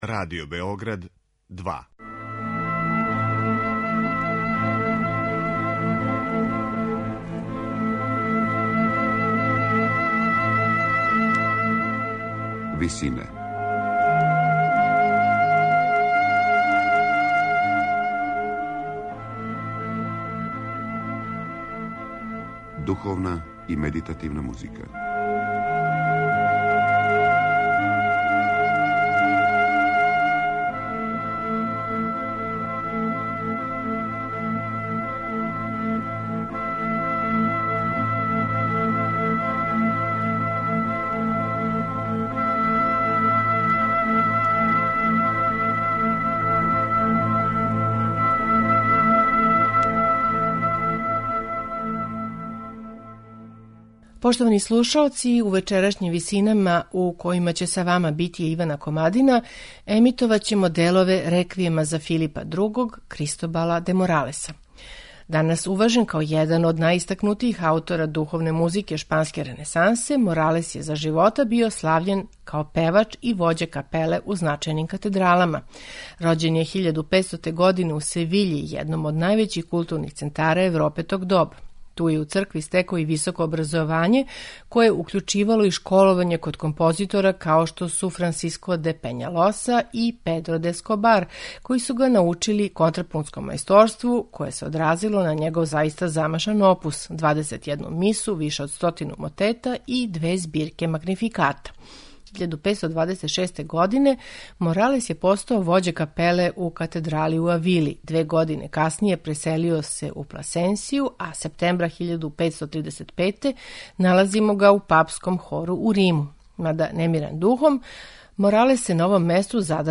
У вечерашњим Висинама Моралесов Реквијем за Филипа Другог слушамо у извођењу ансамбла Gabrieli Consort , под управом Пола Макриша.
медитативне и духовне композиције